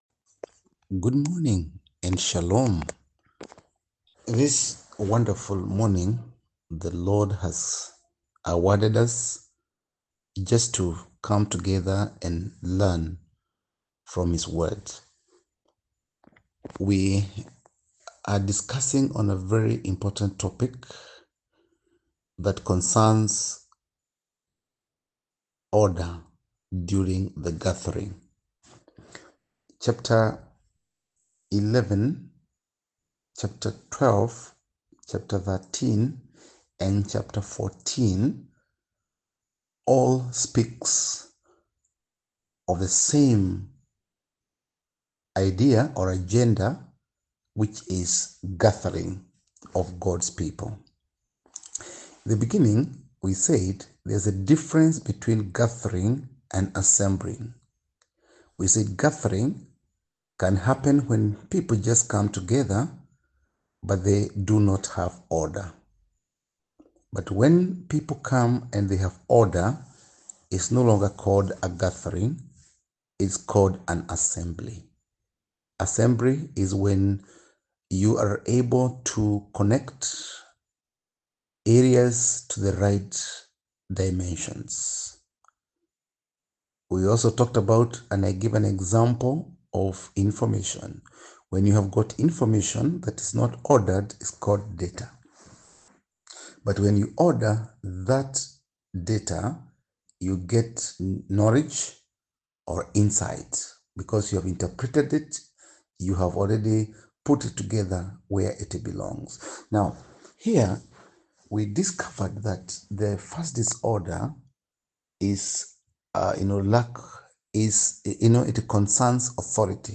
Spiritual dimension of life 1 Corinthians 12: 1-11 1-Corinthians-12-1-11 Audio Summary 1 Corinthians 12:1-11 Context Paul addresses order in church gatherings, distinguishing between unordered gatherings and ordered assemblies where parts connect properly, like turning data into knowledge.